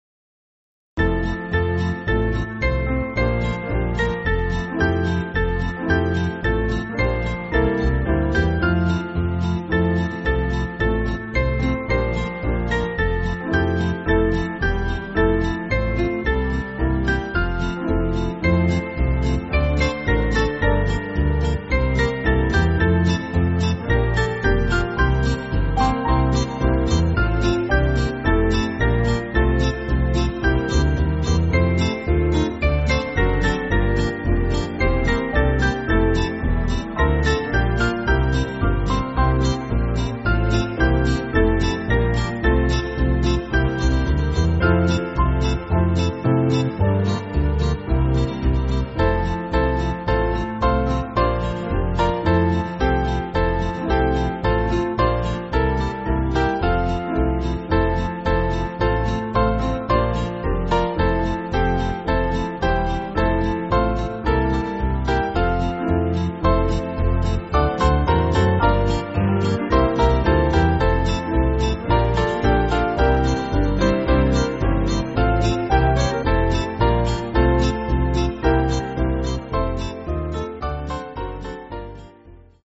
Small Band
(CM)   6/F-Gb